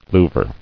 [lou·ver]